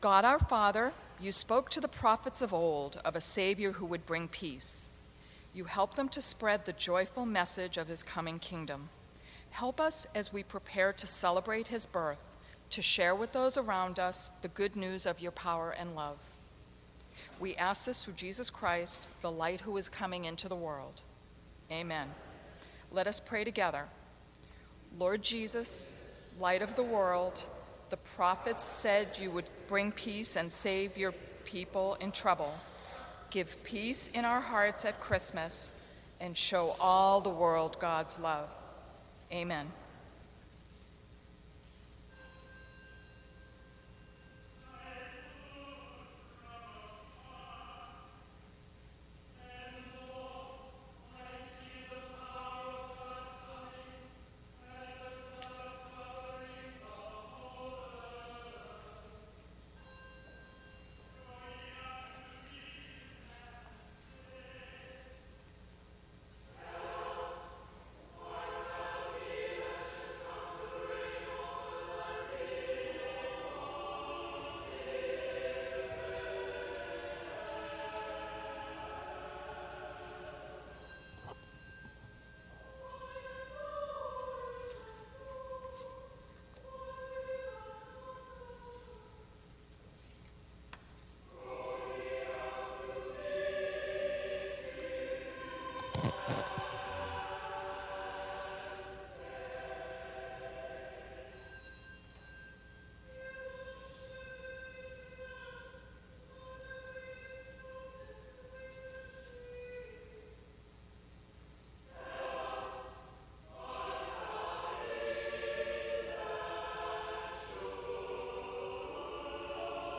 This annual tradition of Lessons and Carols is a service of scripture and song that dates to the late 19th century. In this service, we listen to several readings which recount our story.